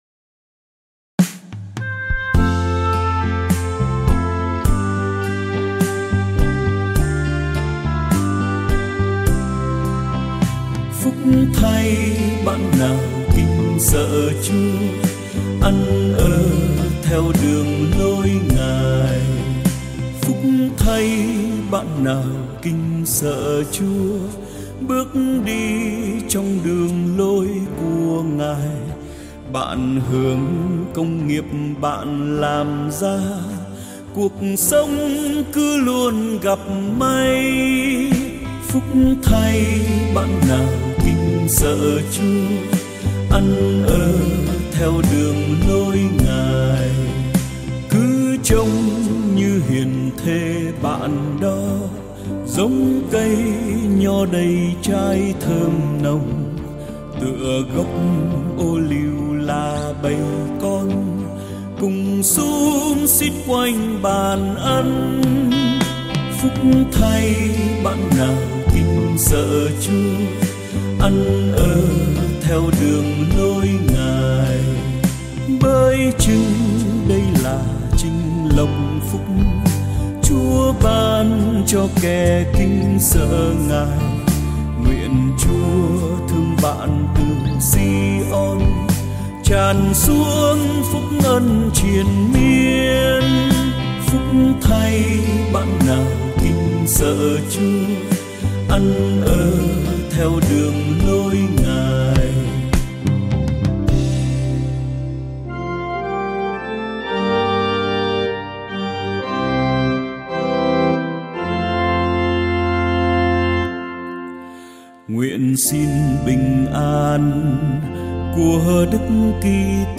Thể loại 🌾 Nhạc Thánh Ca, 🌾 Thánh Vịnh - Đáp Ca